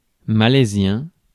Prononciation
Synonymes malais malaysien Prononciation France: IPA: [ma.lɛ.zjɛ̃] Le mot recherché trouvé avec ces langues de source: français Les traductions n’ont pas été trouvées pour la langue de destination choisie.